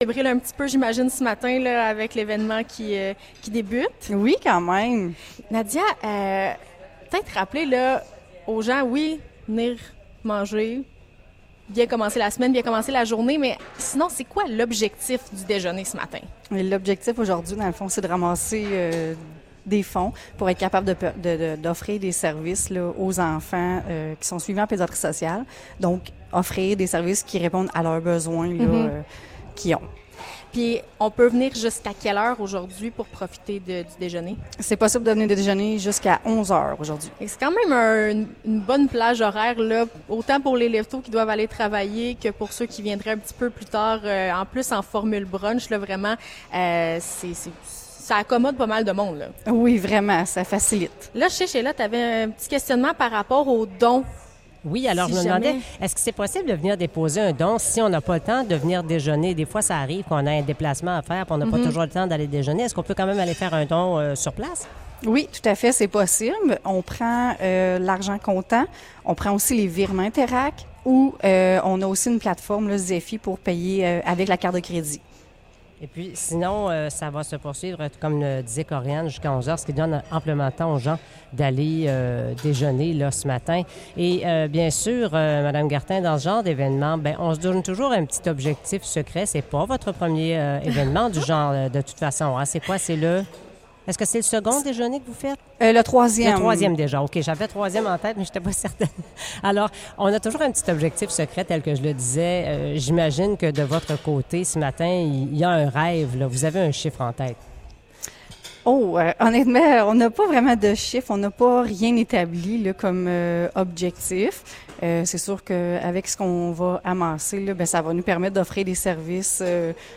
Déjeuner de la Maison de la famille 2023 : entrevue